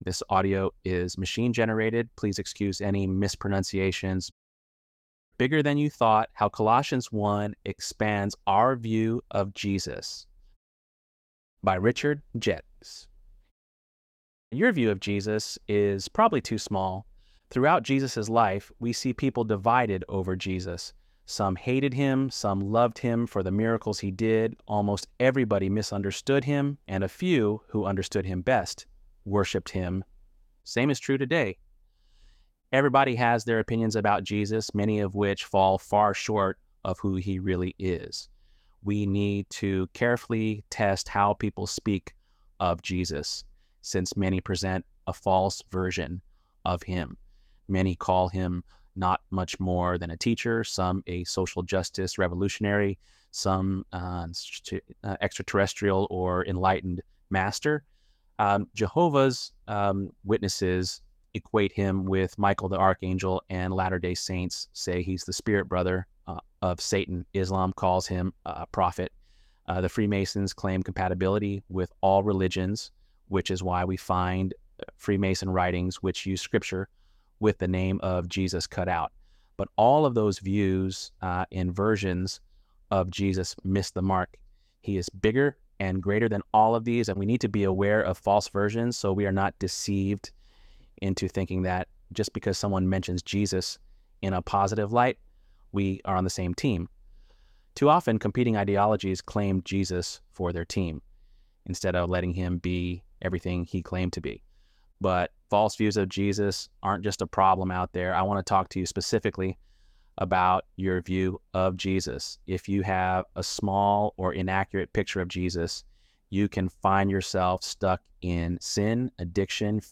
ElevenLabs_10.22.mp3